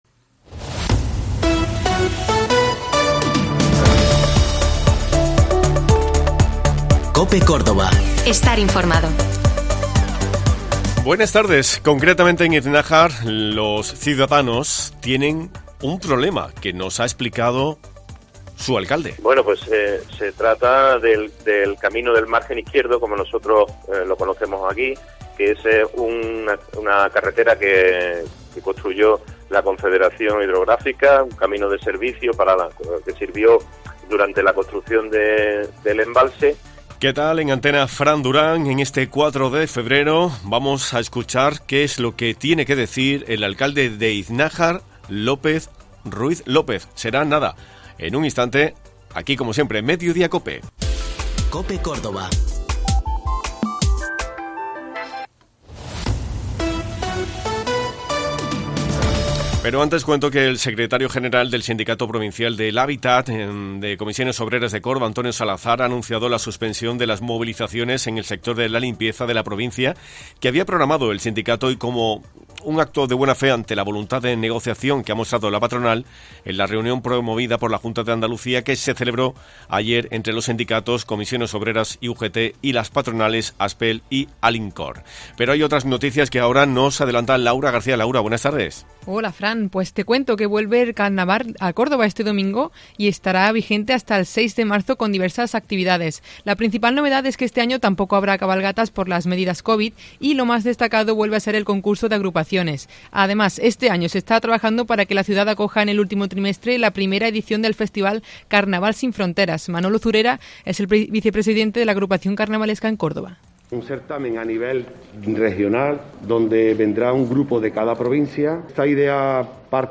El Ayuntamiento viene reclamando a la CHG su arreglo sin obtener respuesta. Hemos hablado con su alcalde, Lope Ruiz López, quien nos ha explicado cuál es la situación.